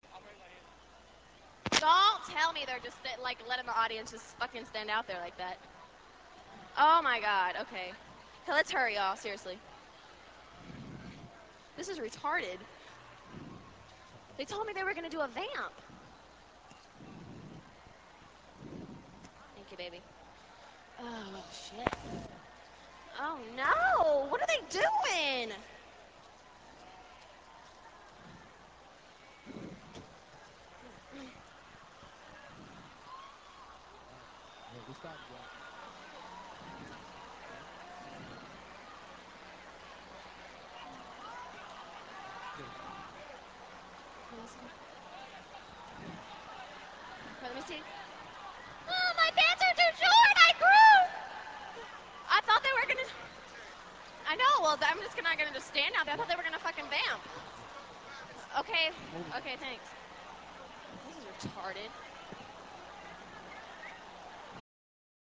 Well since you're here, why not download the sound clip of Britney throwing a tissy backstage at a concert in Brazil.